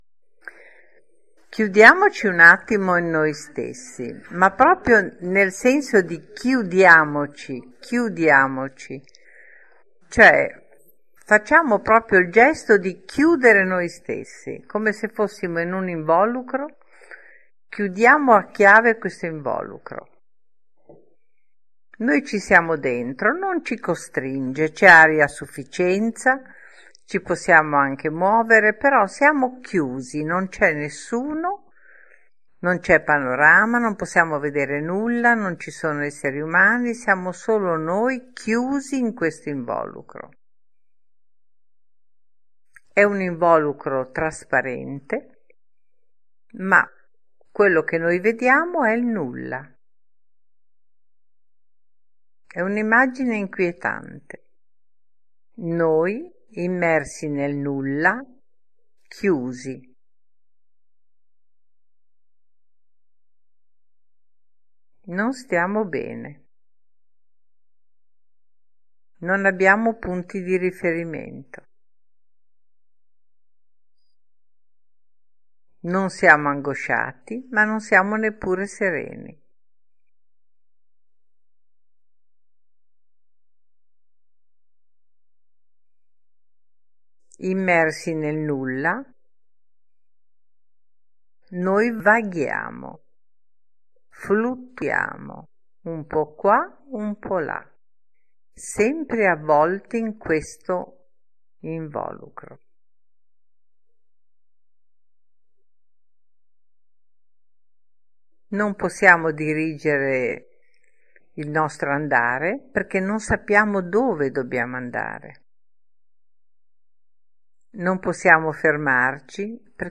Chiudiamoci in Noi Stessi – meditazione
Chiudiamoci-in-noi-stessi-meditazione.mp3